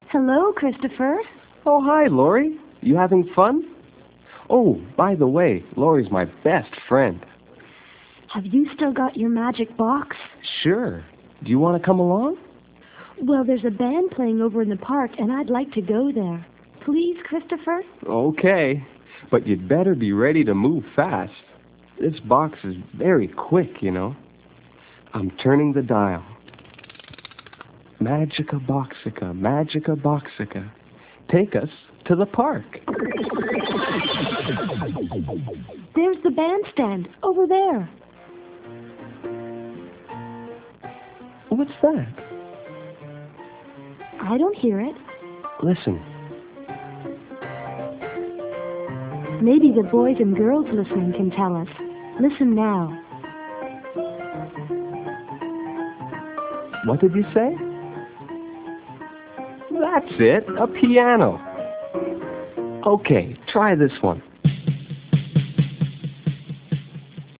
Talking Books
Children will be fascinated and take pride in their ability to recognize animals, instruments and things of everyday life, merely by the sounds they make!